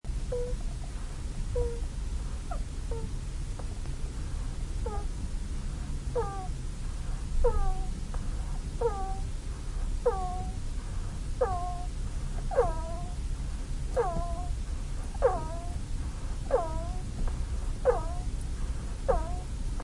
Download Hamster sound effect for free.
Hamster